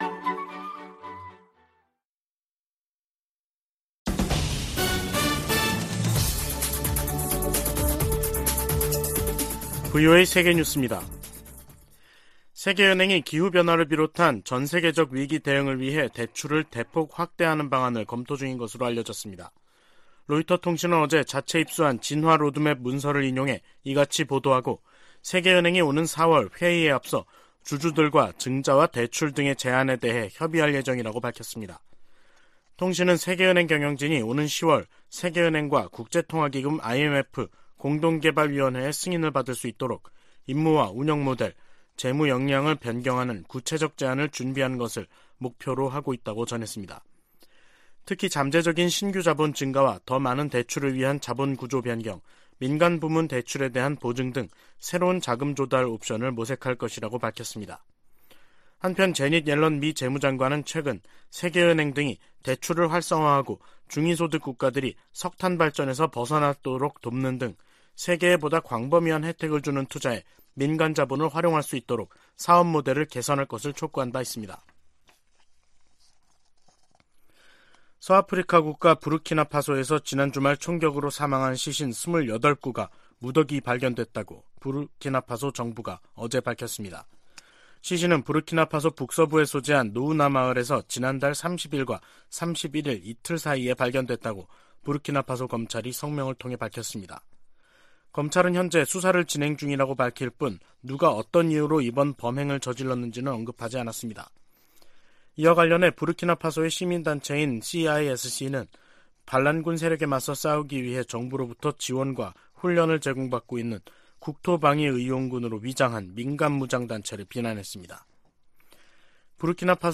VOA 한국어 간판 뉴스 프로그램 '뉴스 투데이', 2023년 1월 3일 2부 방송입니다. 유럽연합과 영국, 캐나다 등이 북한에 군사적 긴장 고조행위를 중단하고 비핵화 대화에 복귀하라고 촉구했습니다. 조 바이든 미국 대통령에게는 북한 핵 문제를 비롯한 세계적인 핵무기 위험이 새해에도 주요 외교적 도전이 될 것이라고 미국 외교전문지가 지적했습니다.